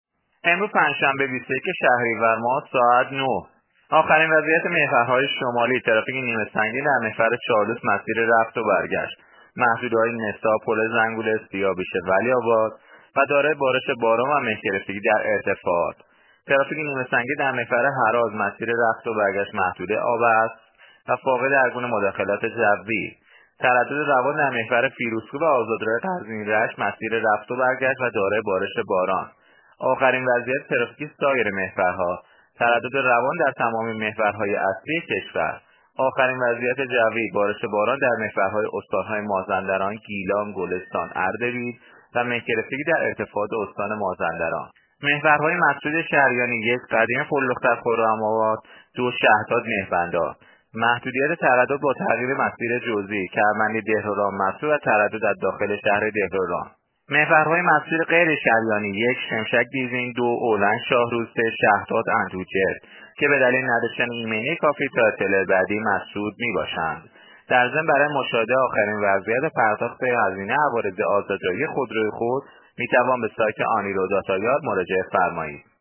گزارش آخرین وضعیت ترافیکی و جوی جاده‌های کشور را از رادیو اینترنتی پایگاه خبری وزارت راه و شهرسازی بشنوید.